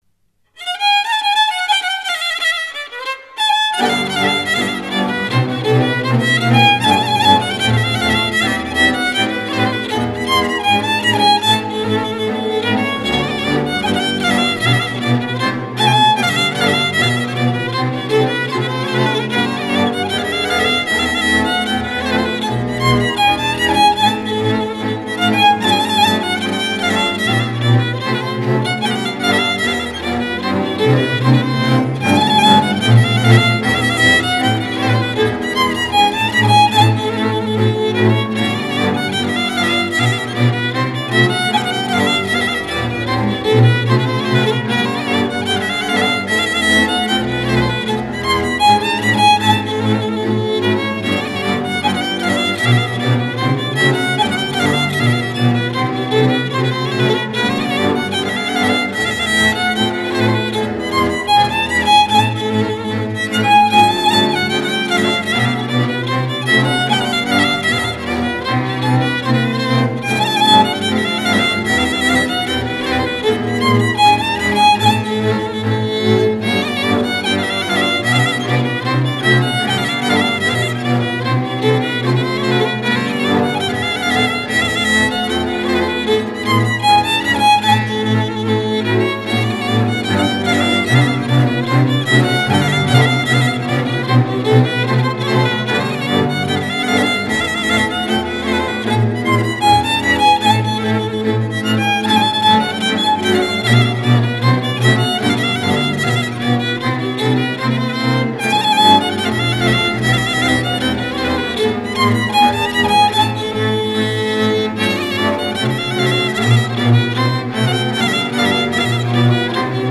Grupul instrumental din Viișoara